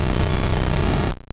Cri de Magnéton dans Pokémon Or et Argent.